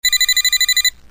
telefono
Sonido FX 36 de 42
telefono.mp3